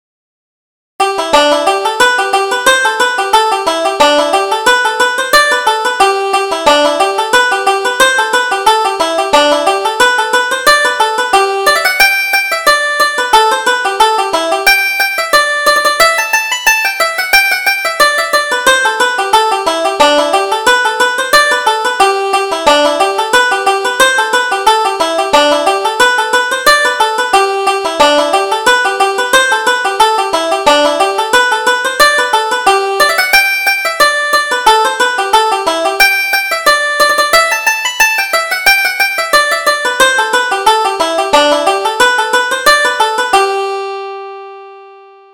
Reel: The Flower of the Flock